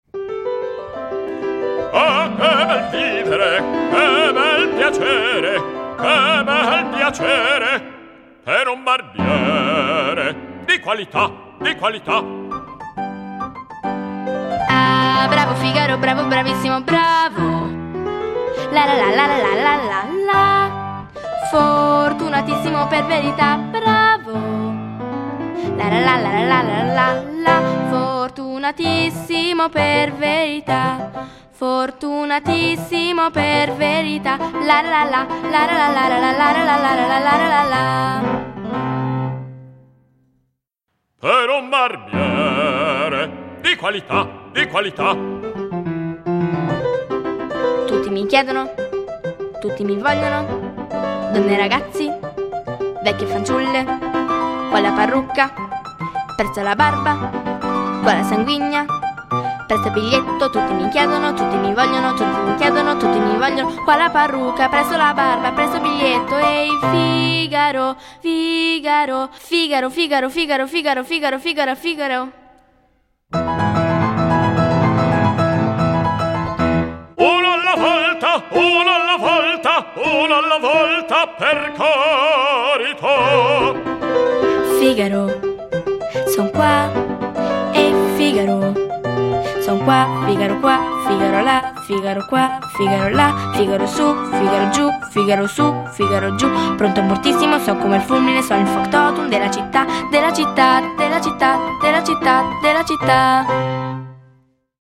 Cavatina, Largo al factotum: lettura ritmica